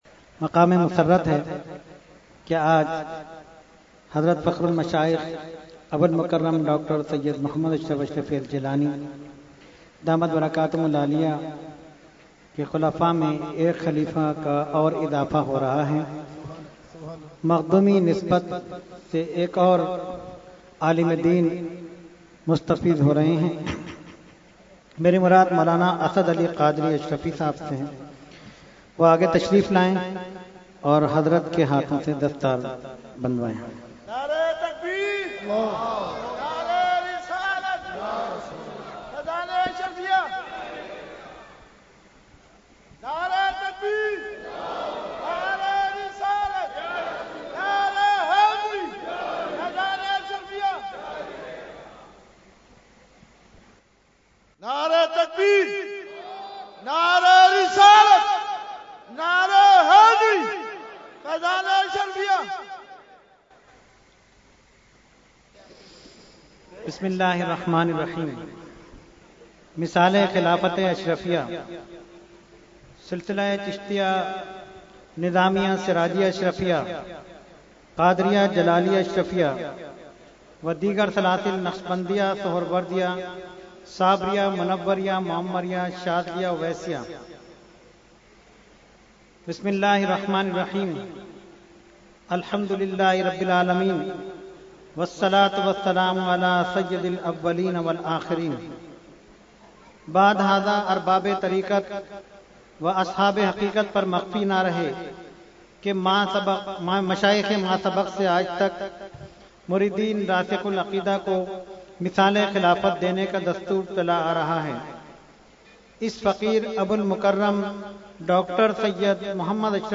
Weekly Tarbiyati Nashist held on 4/12/2011 at Dargah Alia Ashrafia Ashrafabad Firdous Colony Karachi.